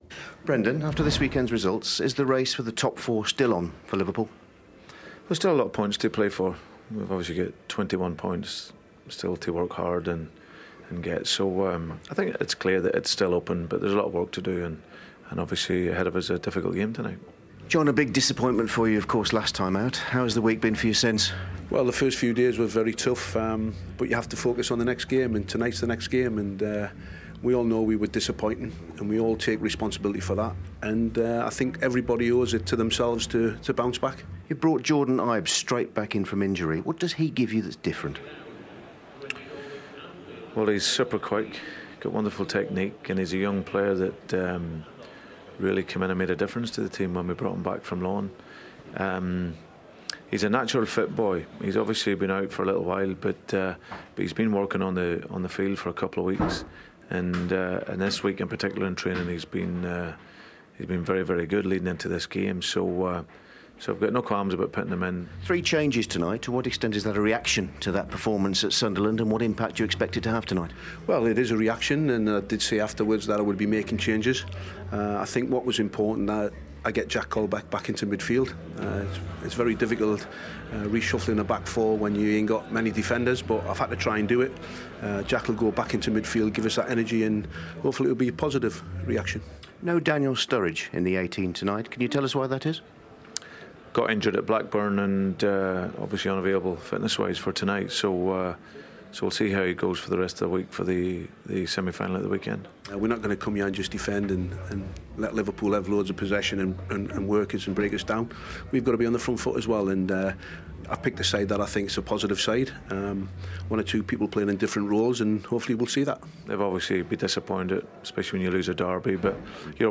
Hear from both managers ahead of tonight's Monday Night Football from Anfield.